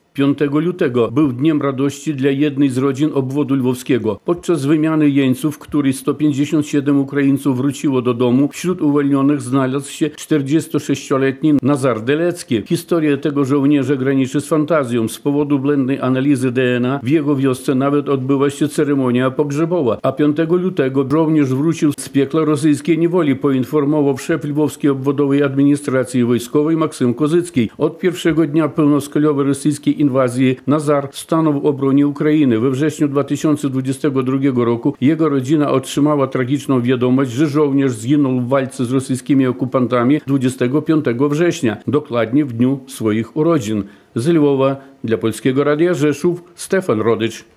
Ze Lwowa dla Polskiego Radia Rzeszów